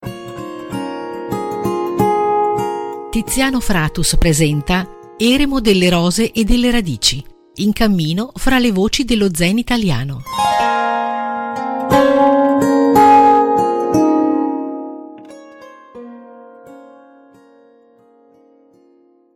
Sigla